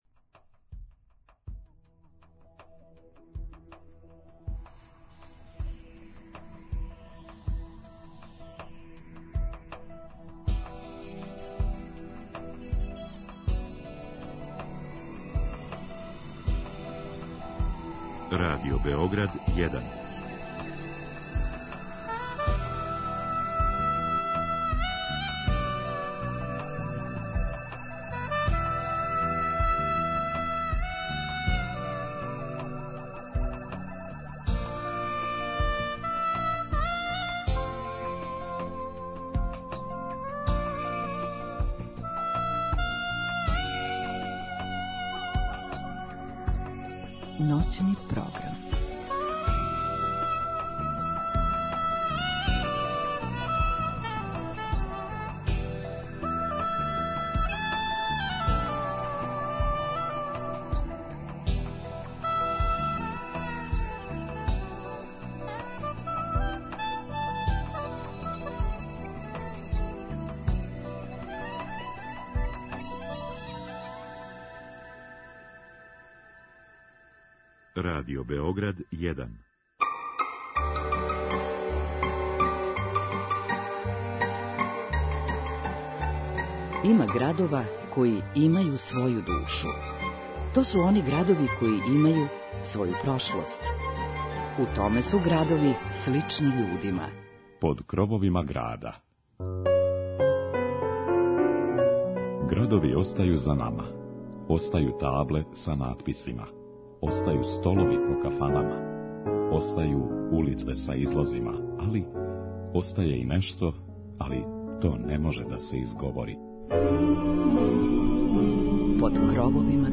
Ове ноћи,у предпразничном расположењу, музиком ћемо прошетати кроз све градове о којима смо говорили током целе 2021. године.